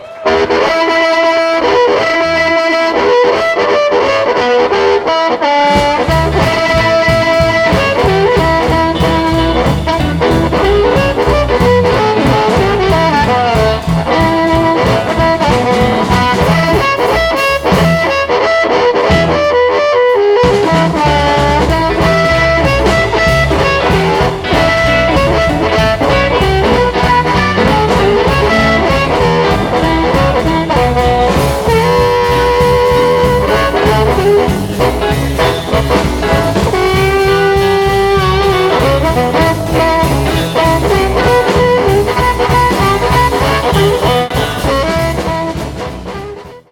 It's a fast paced show